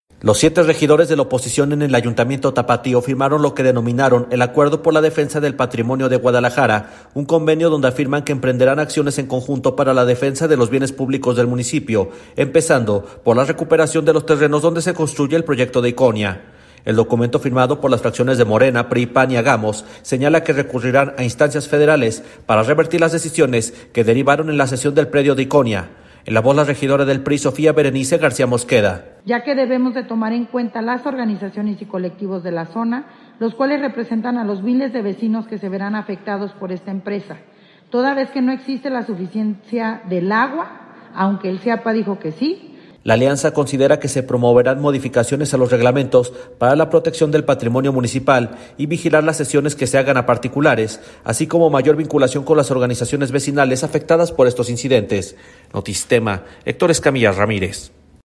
El documento firmado por las fracciones de Morena, PRI, PAN y Hagamos, señala que recurrirán a instancias federales para revertir las decisiones que derivaron en la cesión del predio de Iconia. En la voz la regidora del PRI, Sofía Berenice García Mosqueda.